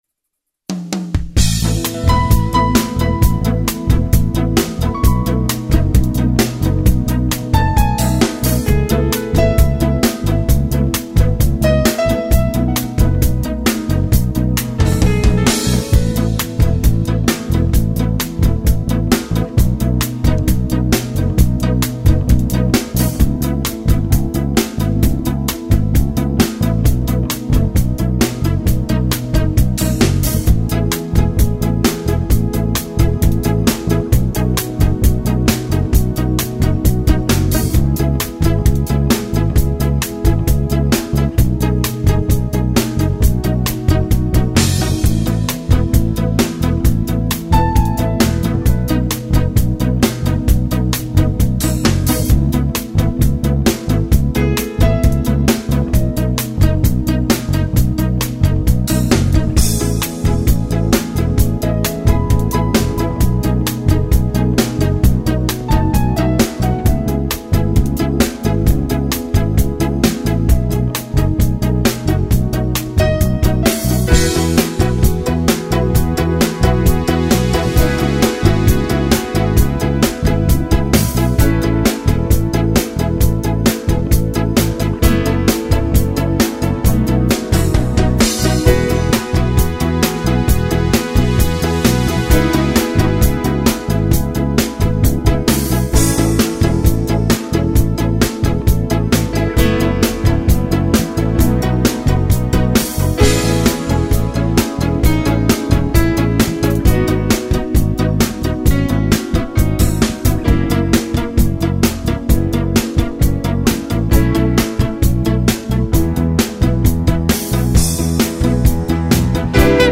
Basi